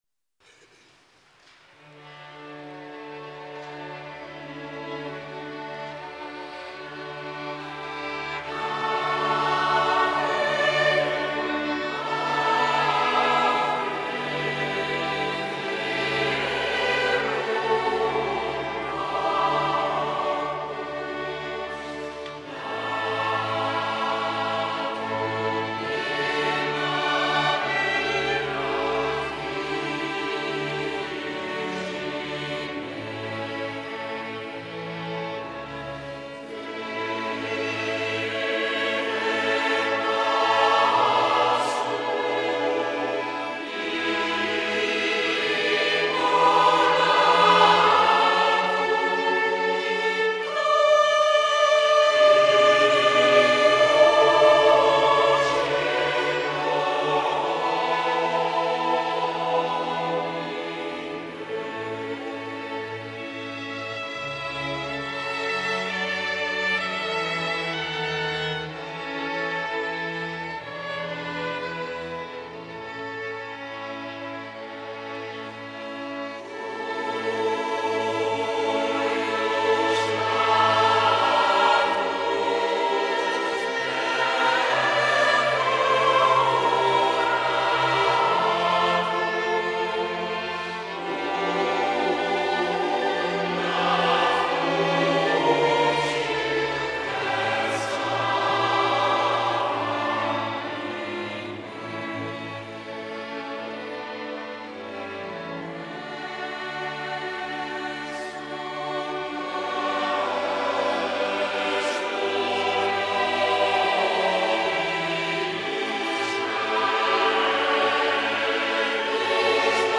Koorklanken